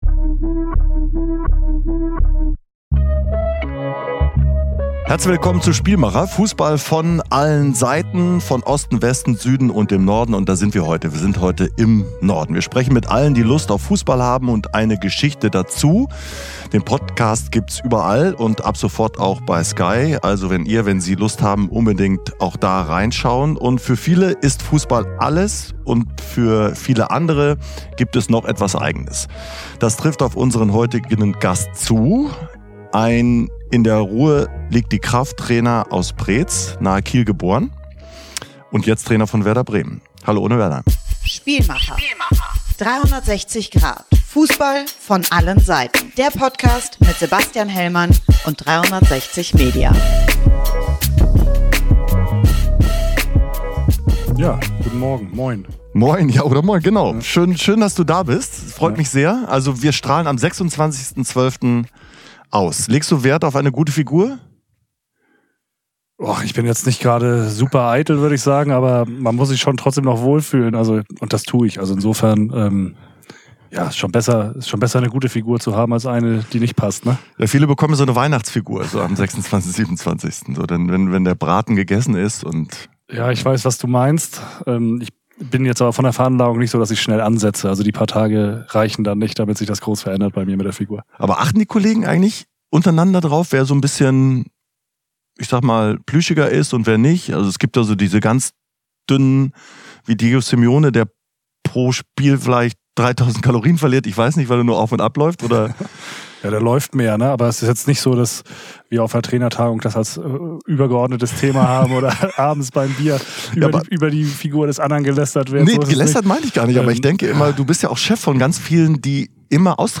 Und mit ihr auch Ole Werner, Cheftrainer von Werder Bremen. Er wagt mit Sebastian Hellmann einen Ausblick auf 2025.